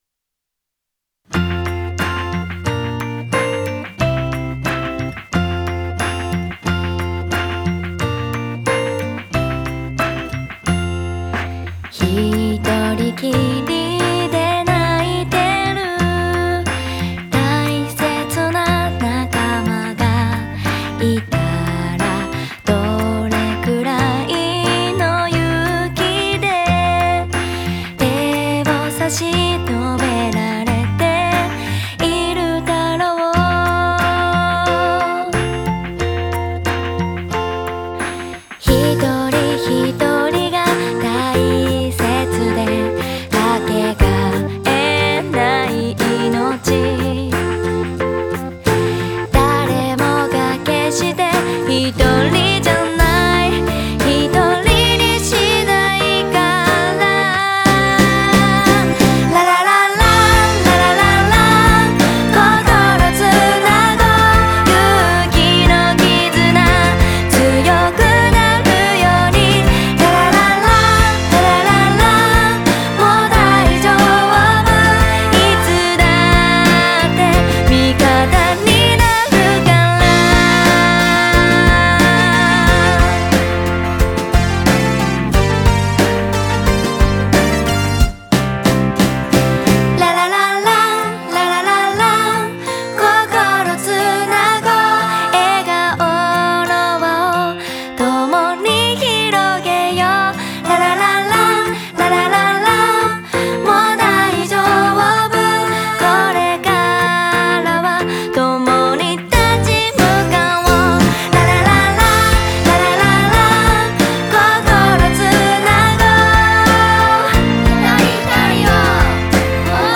フィナーレ～心ひとつにみんなで声をあわせました～
テーマソング「心つないで」を大合唱